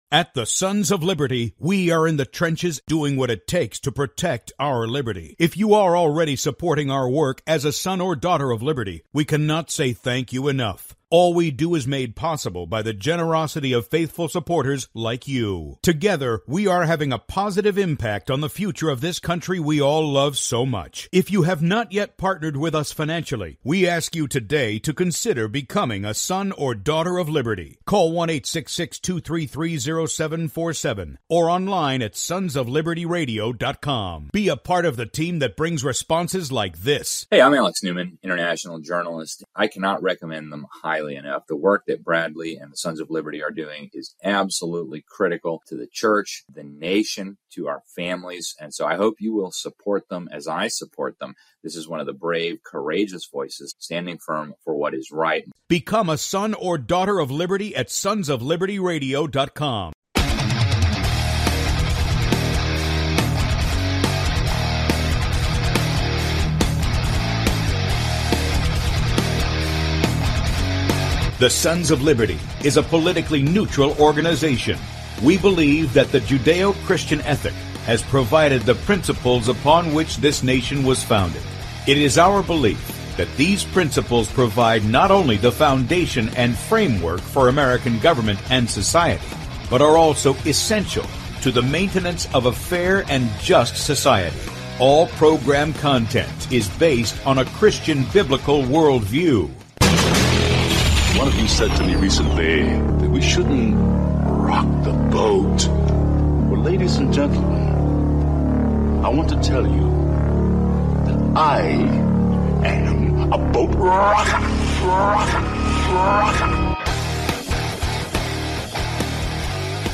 Talk Show Episode, Audio Podcast, Sons of Liberty Radio and Political Blasphemy on , show guests , about Political Blasphemy, categorized as Entertainment,News,World News,Philosophy,Politics & Government,Christianity,Society and Culture,TV & Film